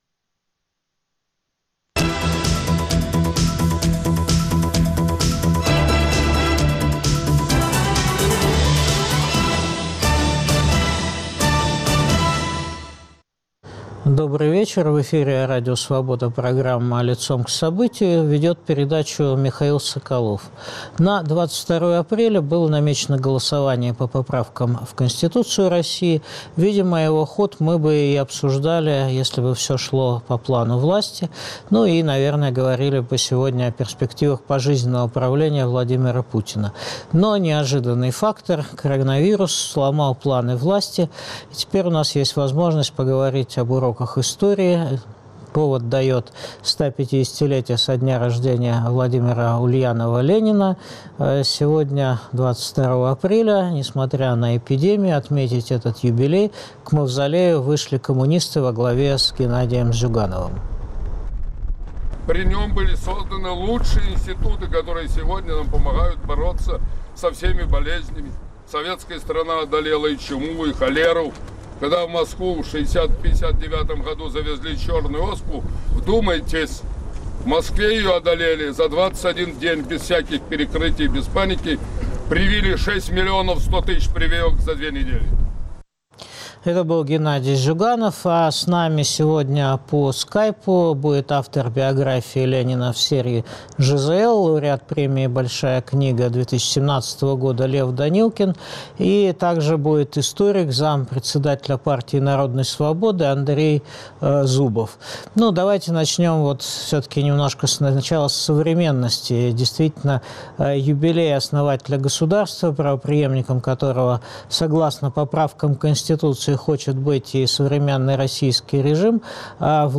Обсуждают автор биографии "Ленин" в серии ЖЗЛ, лауреат премии «Большая книга» 2017 года Лев Данилкин и доктор исторических наук, зам председателя ПАРНАС Андрей Зубов.